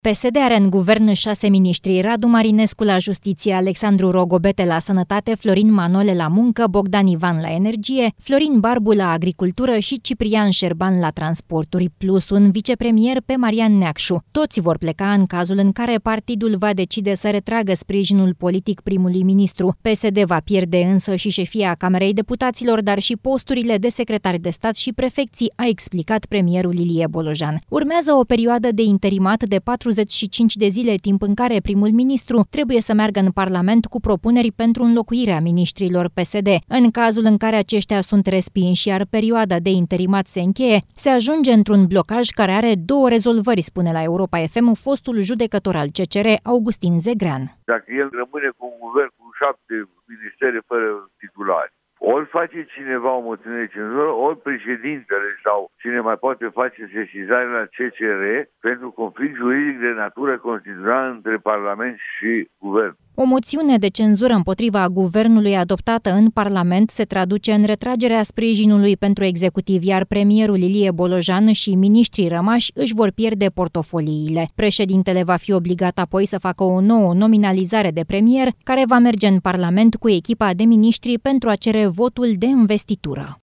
În cazul în care aceștia sunt respinși, iar perioada de interimat se încheie, se ajunge într-un blocaj care are două rezolvări spune la Europa FM, fostul judecător al CCR, Augustin Zegrean.